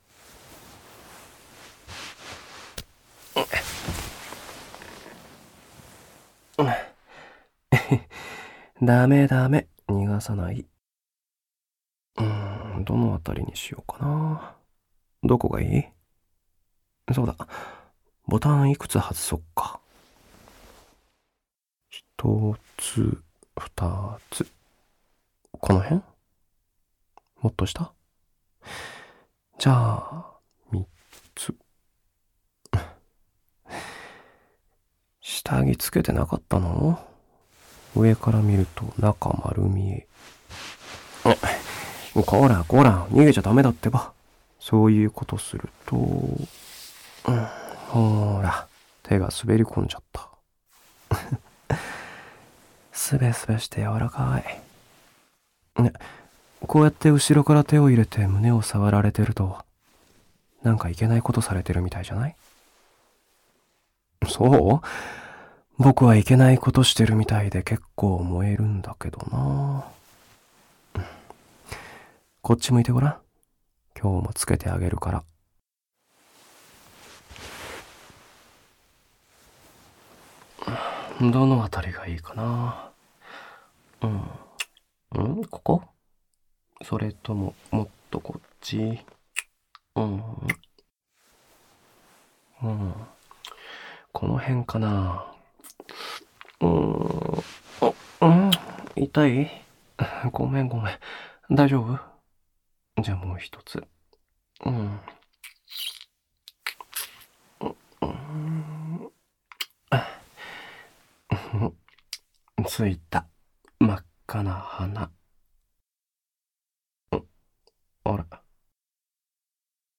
●全編ダミーヘッドマイクにて収録
ほのぼのDJをこころがけました。